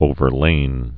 (ōvər-lān)